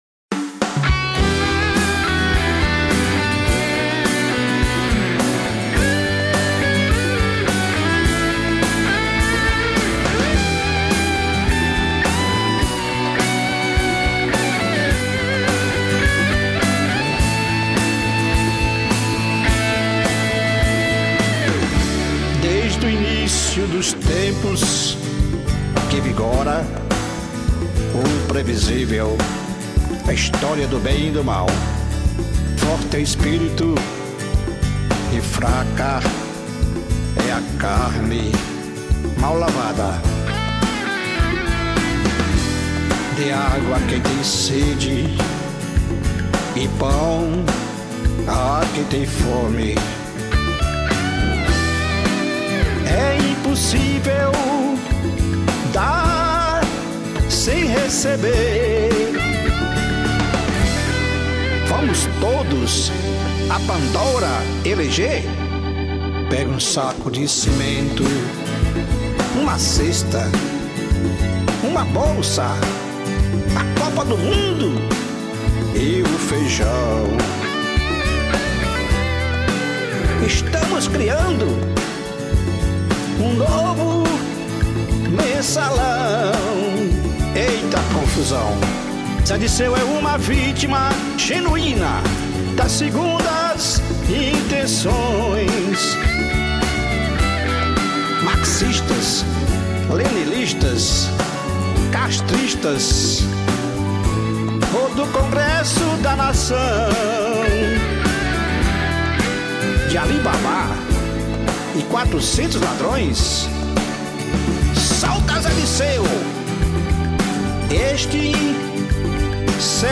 1561   04:50:00   Faixa:     Baião